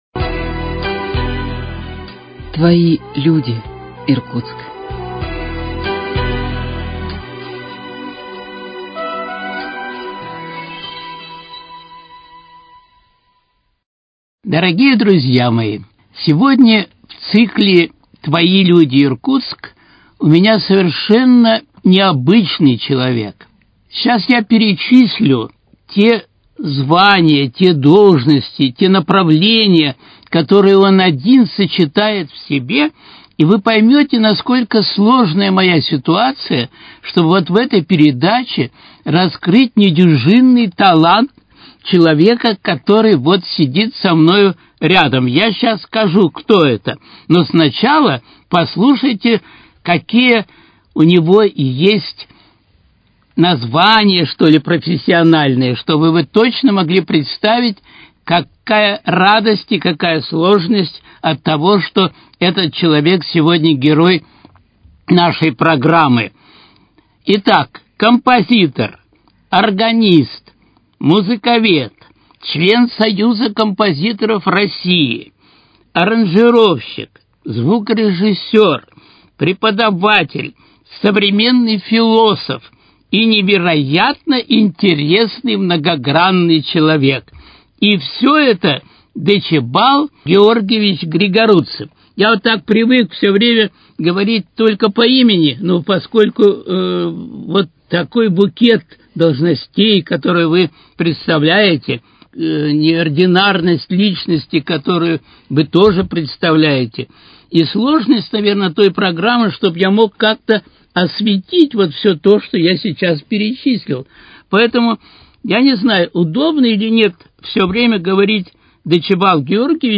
Твои люди, Иркутск: Беседа с композитором